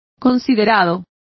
Complete with pronunciation of the translation of considerate.